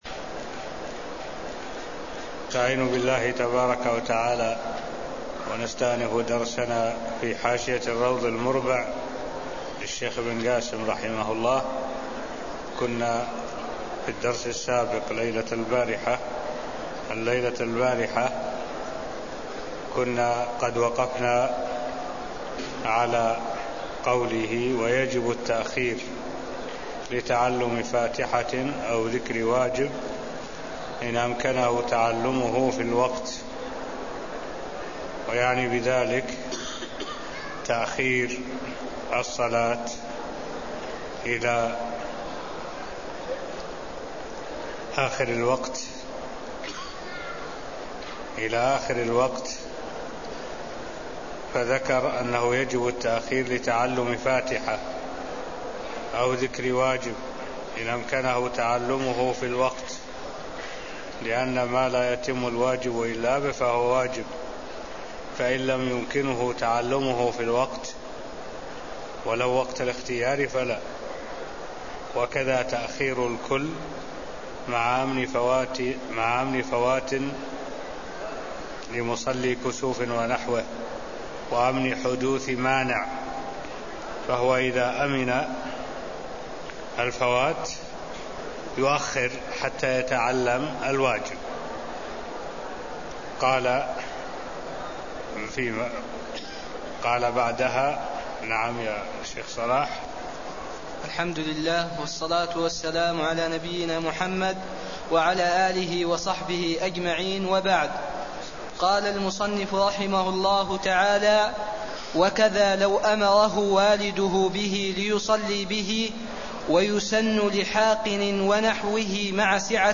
المكان: المسجد النبوي الشيخ: معالي الشيخ الدكتور صالح بن عبد الله العبود معالي الشيخ الدكتور صالح بن عبد الله العبود باب-شروط الصلاة -الوقت (0013)